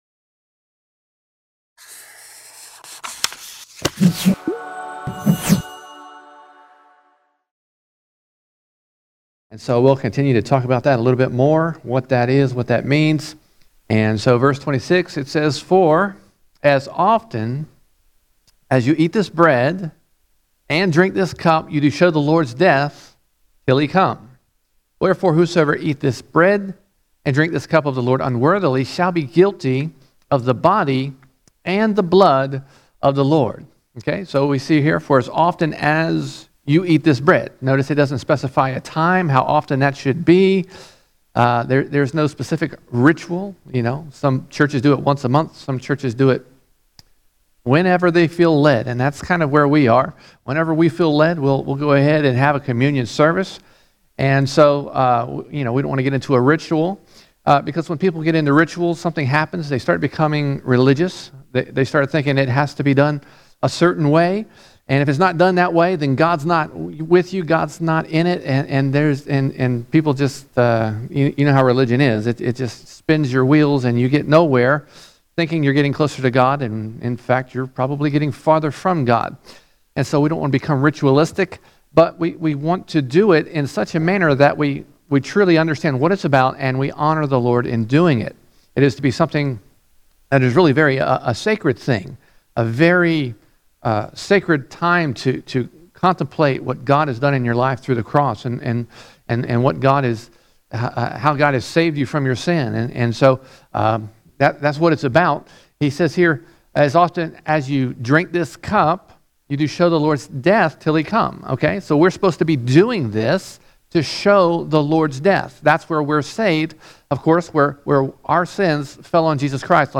21 January 2021 Series: 1 Corinthians All Sermons 1 Corinthians 11:26 to 12:09 1 Corinthians 11:26 to 12:09 Paul teaches us about discerning the Lord's Body when taking the Lord's Supper.